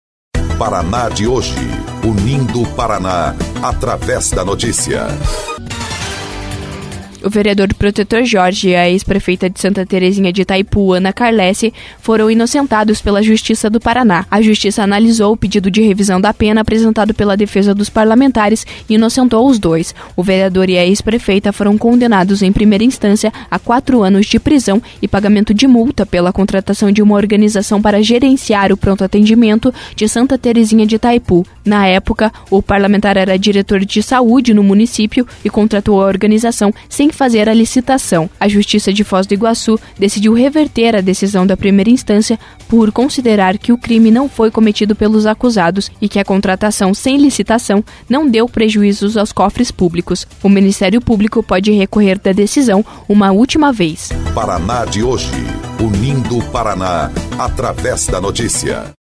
28.08 – BOLETIM – Vereador e ex-prefeita de Santa Terezinha de Itaipu são inocentados pela Justiça de Foz do Iguaçu